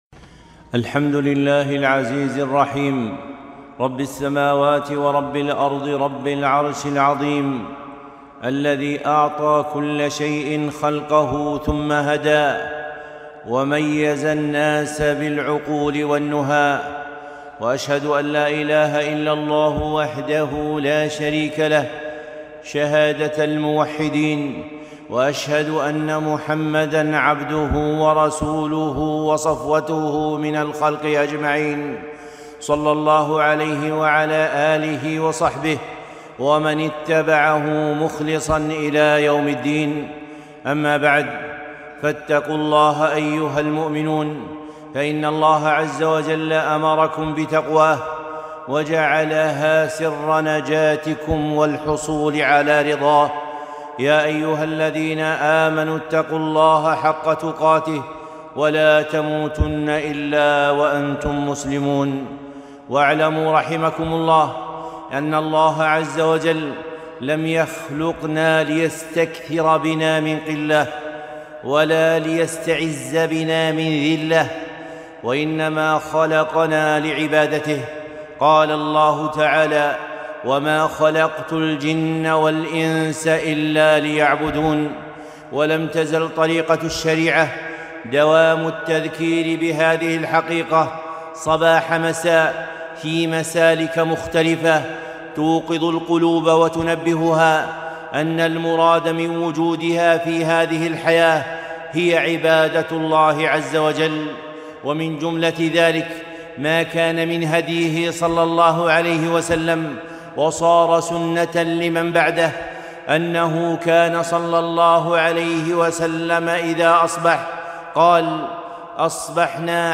خطبة - ميثاق الصباح